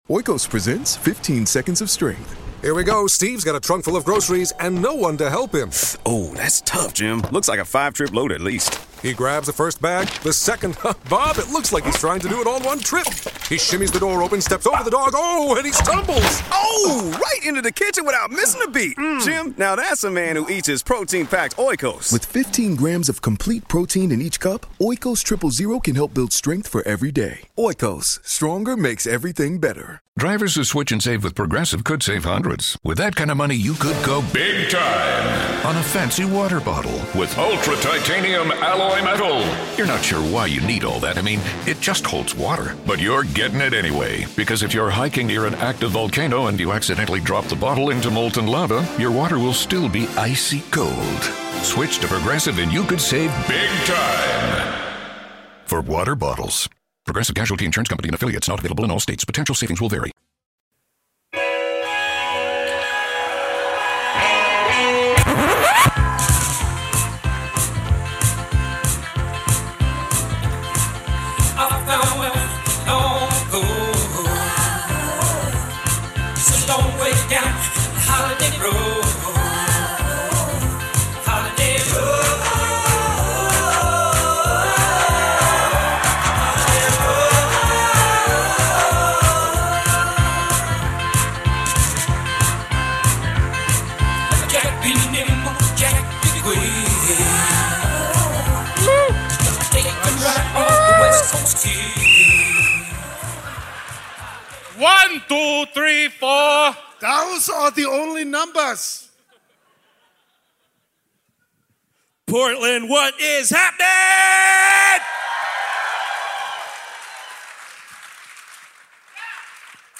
S10: Episode 454 - Kindergarten Cop (Live in Portland)